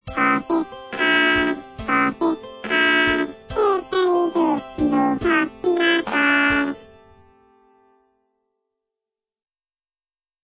2011-05-20(Fri) チープに歌うLinux「妖音リナ」
「発声」には、以前も使ったfestivalをそのまま使う。
tulip_vocal.wav.mp3